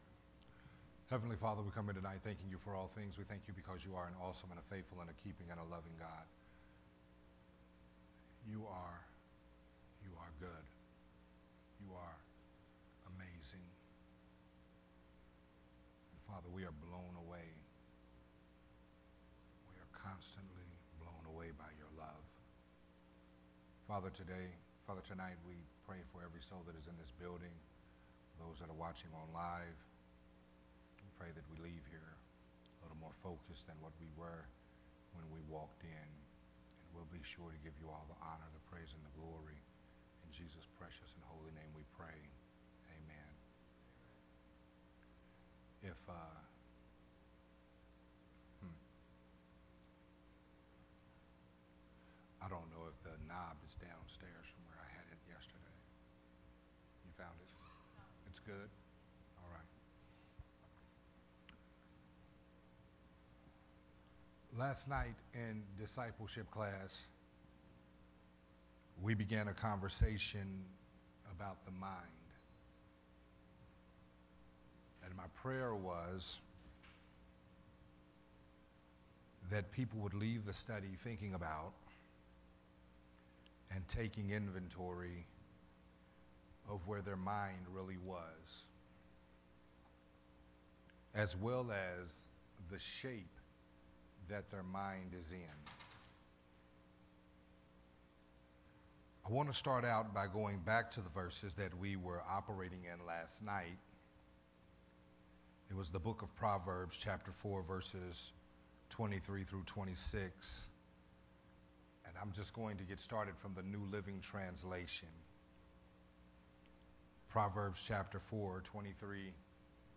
a Family Training Hour teaching
recorded at Unity Worship Center